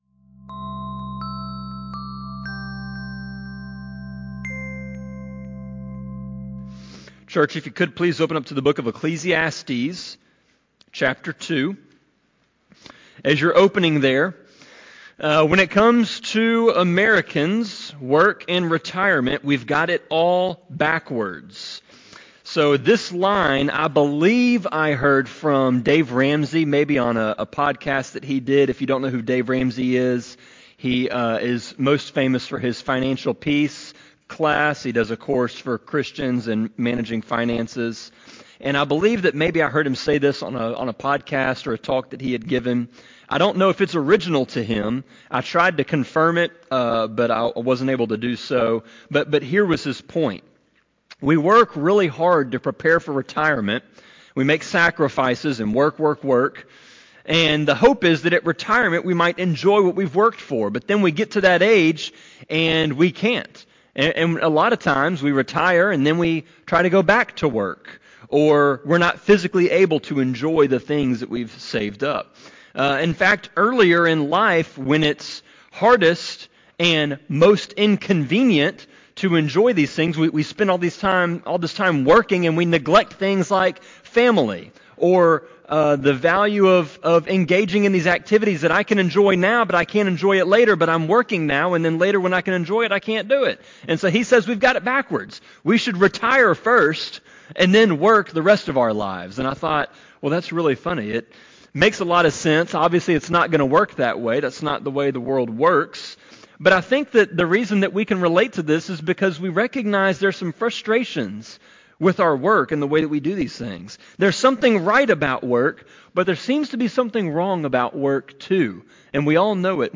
Sermon-25.2.2-CD.mp3